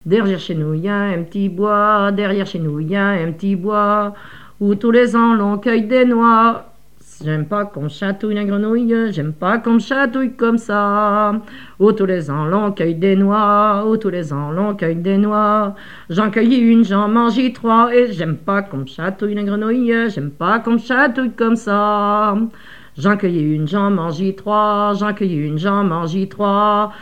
danse : ronde : demi-rond
légendes locales, expressions en patois et chansons
Pièce musicale inédite